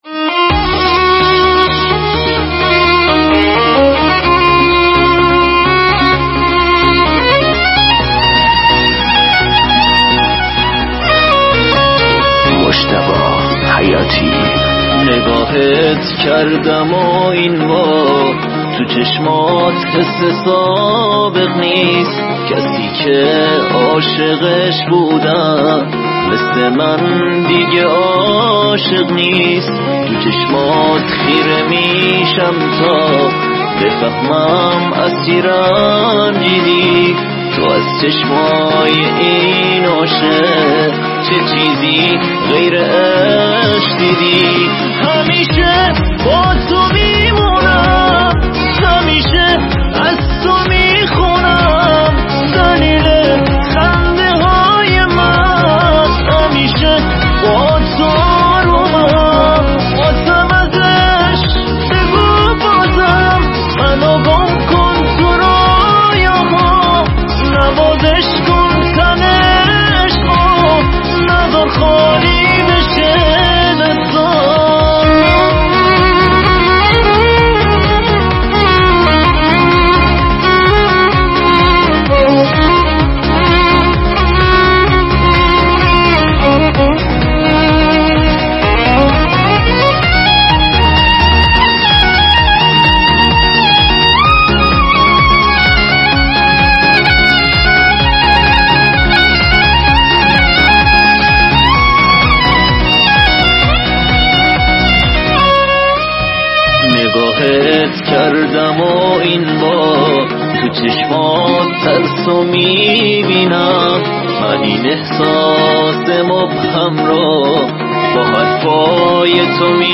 نوازنده ی ویولون